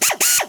sounds / Cosmic Rage / ships / Ratika / repair / 3.wav